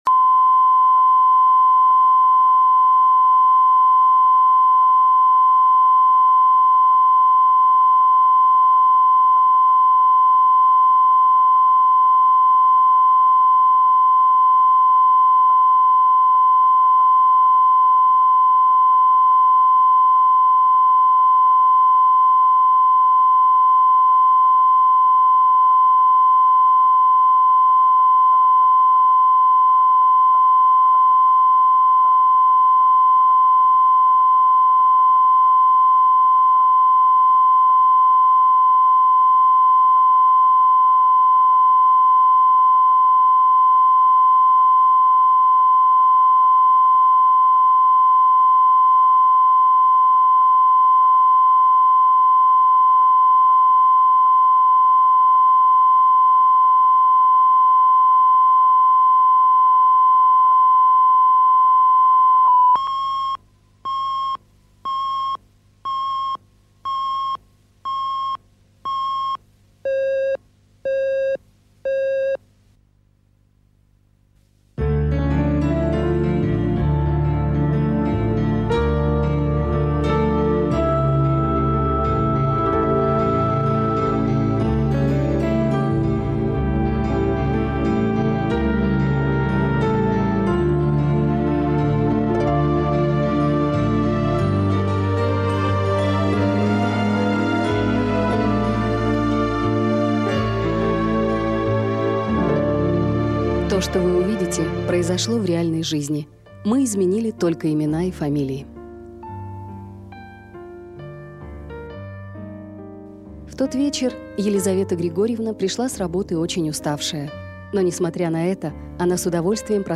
Аудиокнига Дочки-матери | Библиотека аудиокниг
Прослушать и бесплатно скачать фрагмент аудиокниги